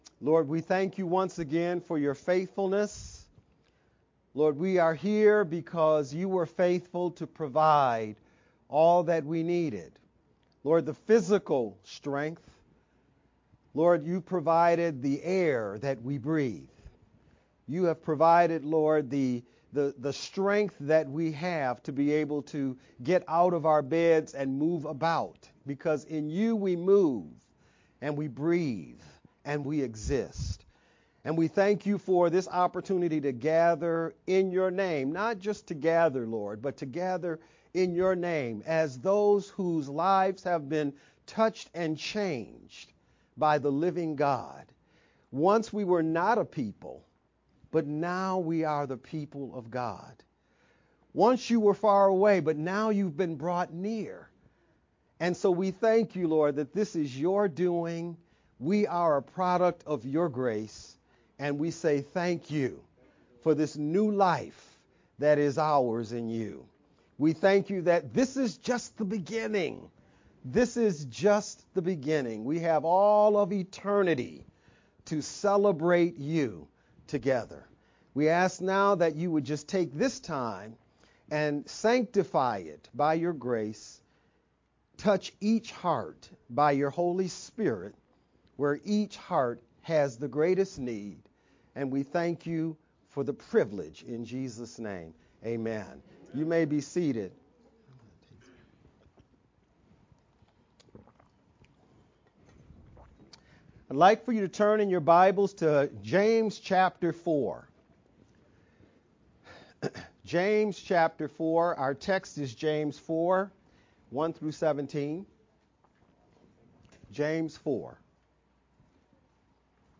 July-21st-VBCC-edited-sermon-only_Converted-CD.mp3